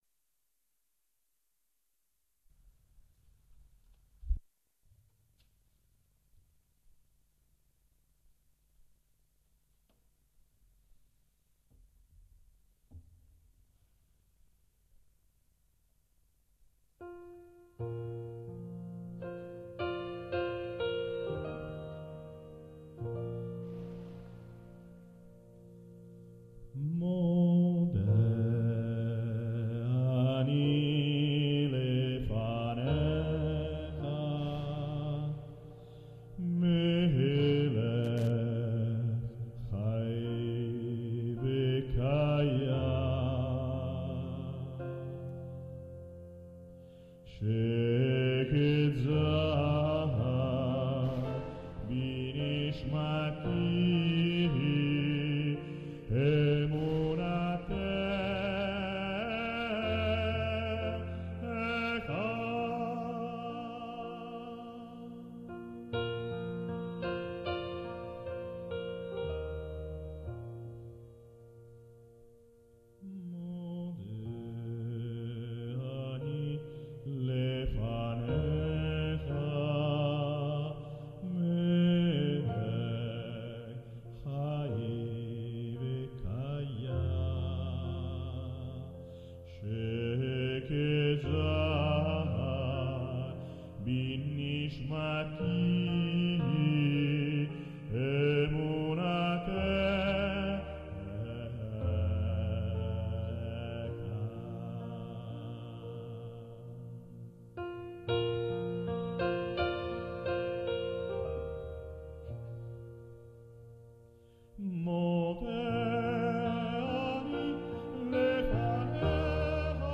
Modè anì lefanecha canto sinagogale in ebraico
piano
registrazione effettuata al Teatro Paisiello di Lecce il 27 gennaio 2004